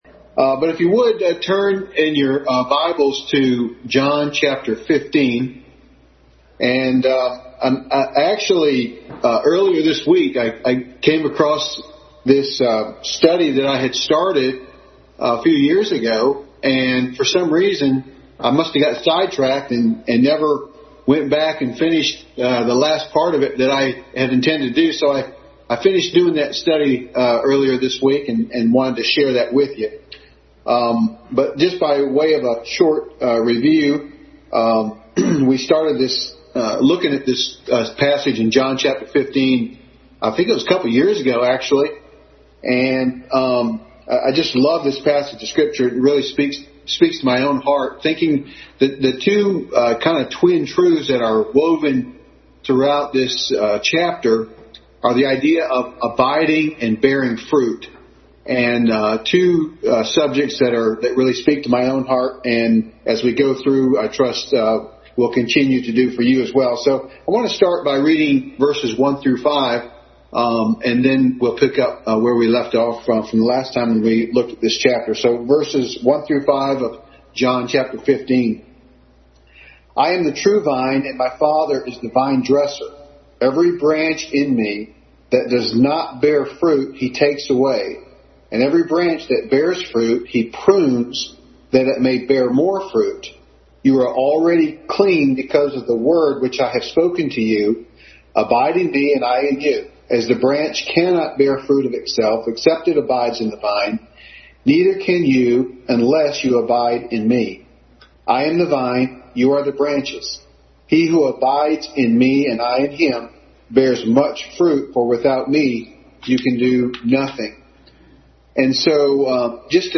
Adult Sunday School Class